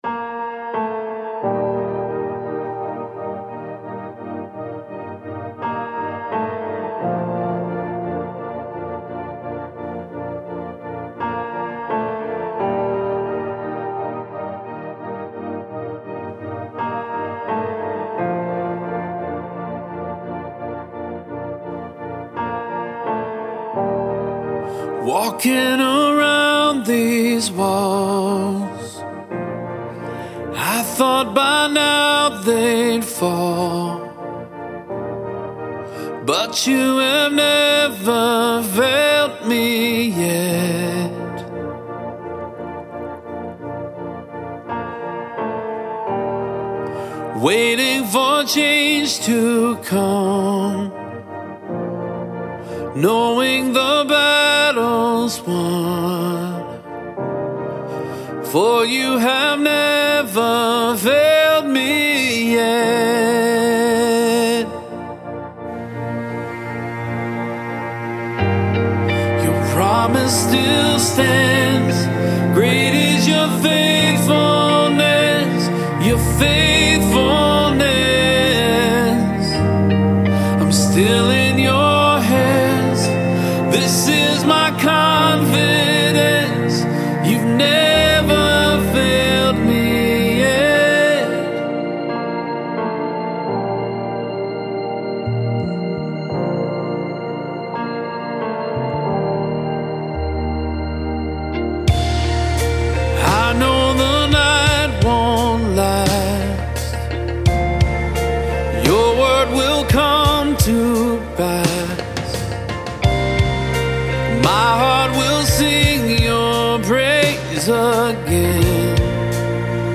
Full arrangement demo
• Tempo: 86 bpm, 4/4 time
STYLE: FULL PRODUCTION
• Drums
• Percussion
• Piano
• Electric Guitar (2 tracks)
• Bass
• Gang Vocals